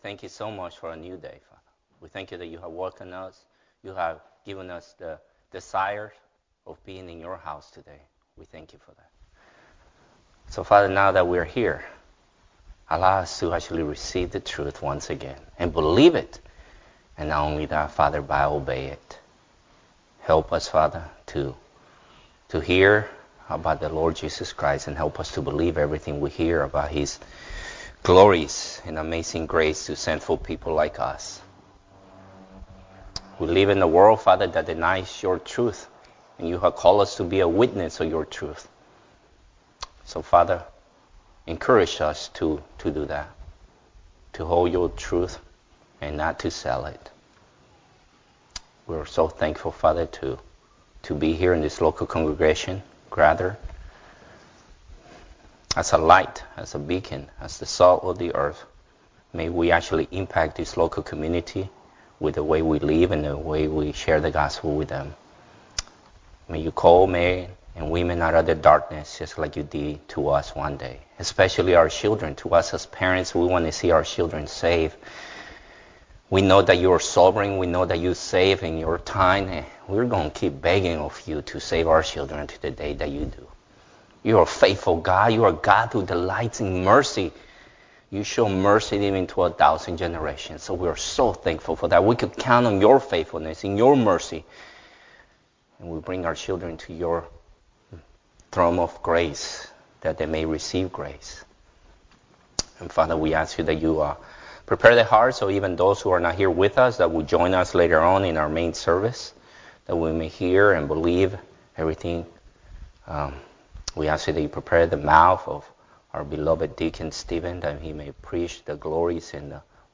Sunday School
Sermon